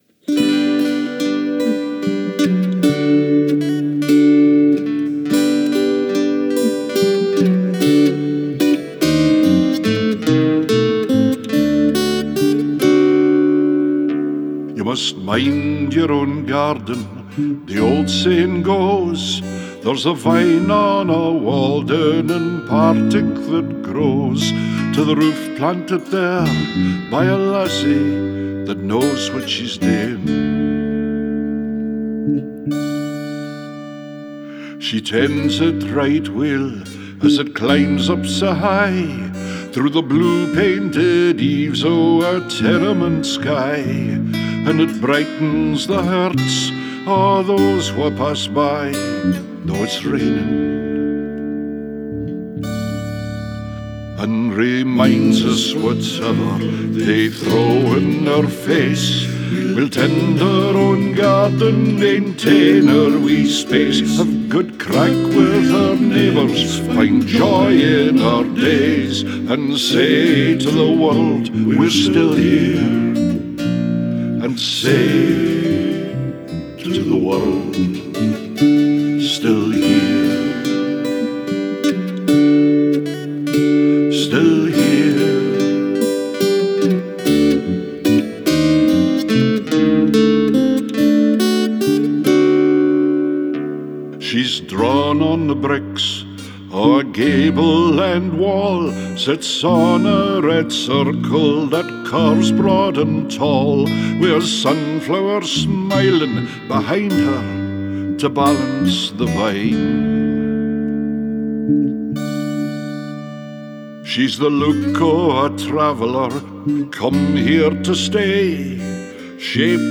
Demo
Scottish Traditional Songwriter